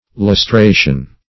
Meaning of lustration. lustration synonyms, pronunciation, spelling and more from Free Dictionary.
Search Result for " lustration" : The Collaborative International Dictionary of English v.0.48: Lustration \Lus*tra"tion\, n. [L. lustratio: cf. F. lustration.] 1.